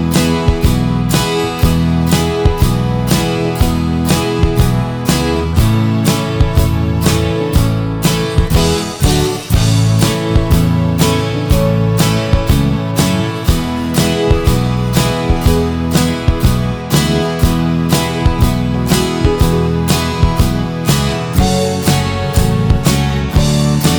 Minus Main Guitar Pop (1970s) 3:37 Buy £1.50